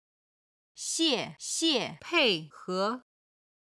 ※音源の都合上、軽声は四声を使っています。